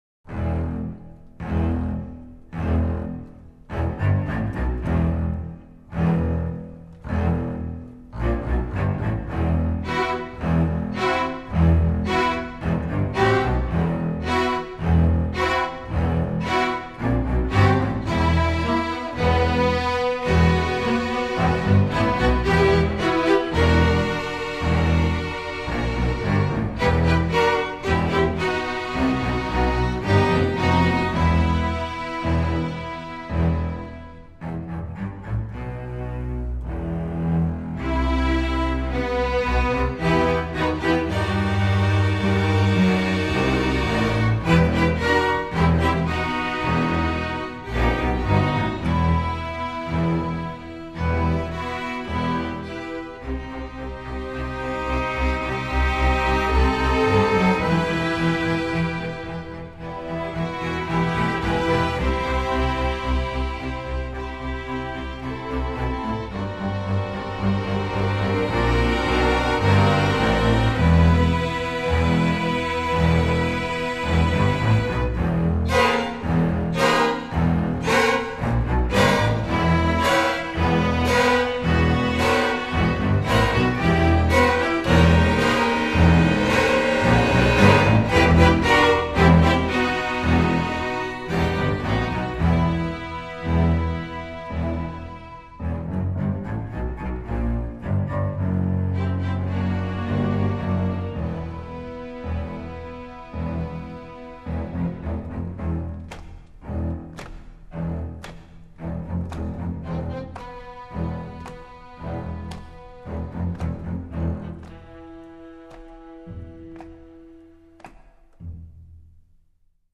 Instrumental Orchestra String Orchestra
String Orchestra